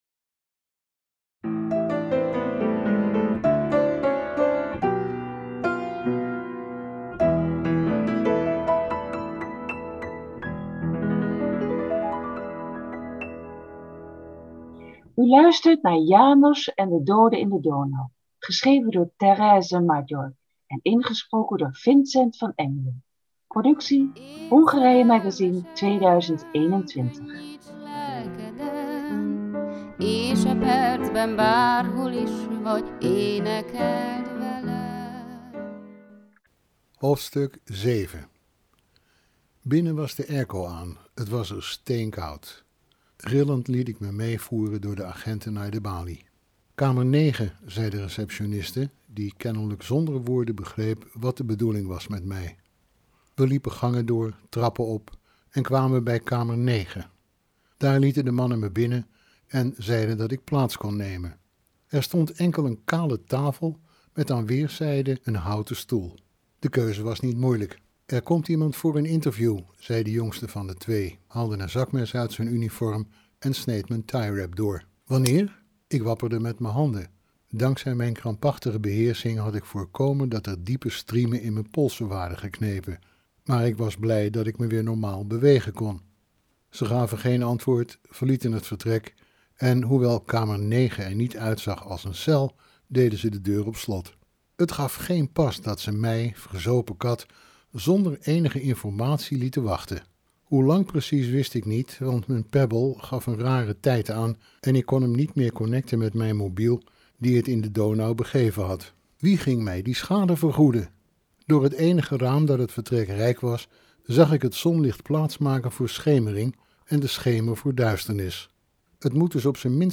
'János en de dode in de Donau' als luisterboek!